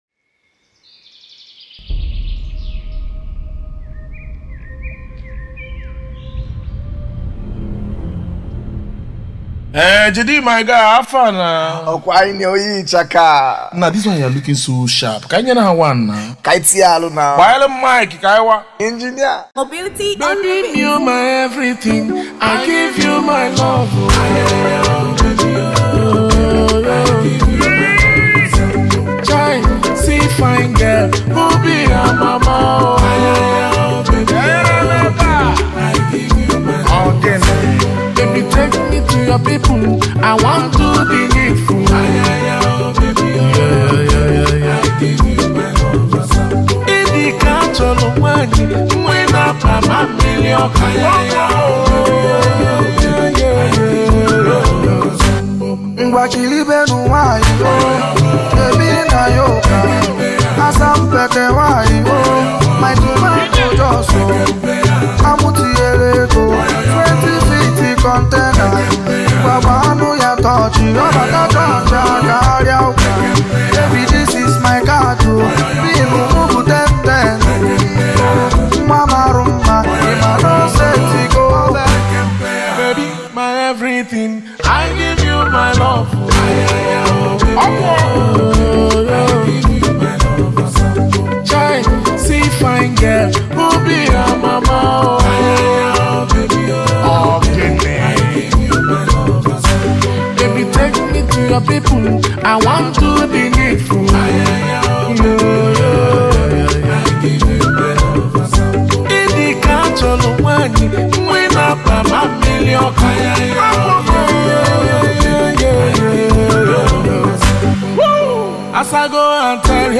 Heavyweight Igbo Music and Highlife
Highlife